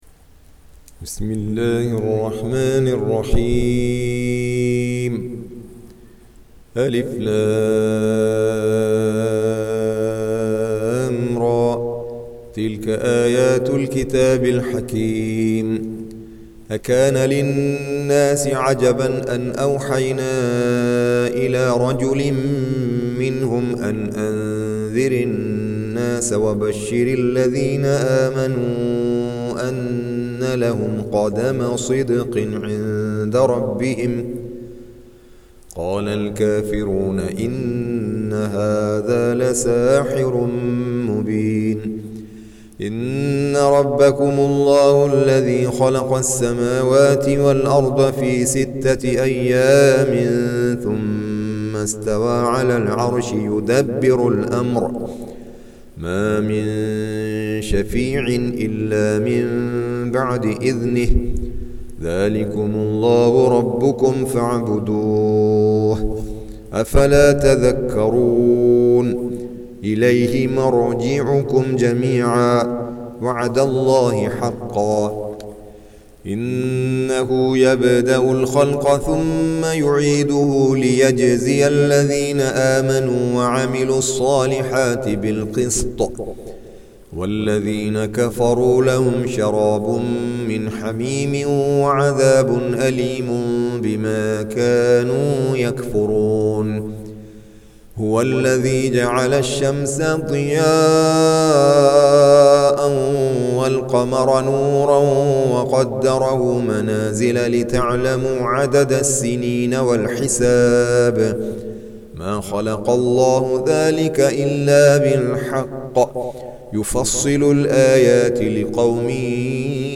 Surah Sequence تتابع السورة Download Surah حمّل السورة Reciting Murattalah Audio for 10. Surah Y�nus سورة يونس N.B *Surah Includes Al-Basmalah Reciters Sequents تتابع التلاوات Reciters Repeats تكرار التلاوات